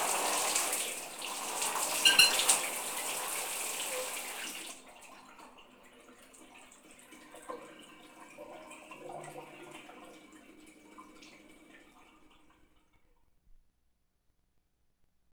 ShowerEnd.wav